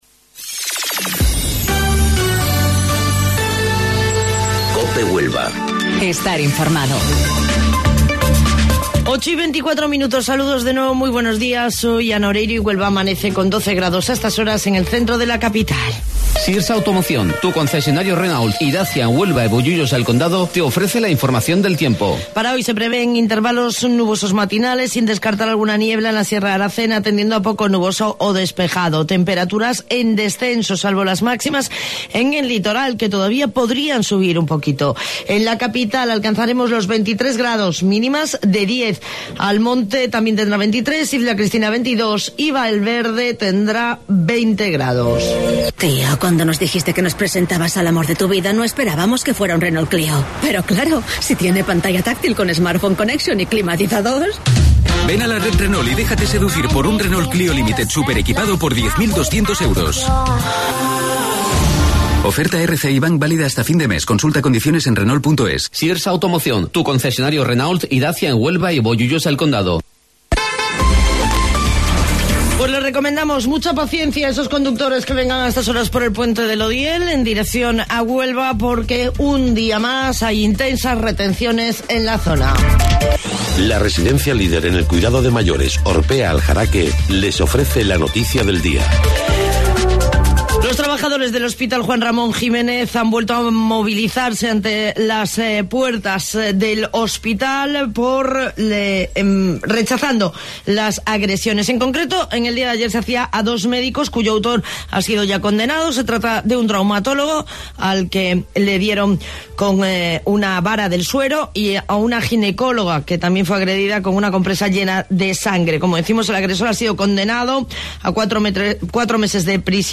AUDIO: Informativo Local 08:25 del 13 de Marzo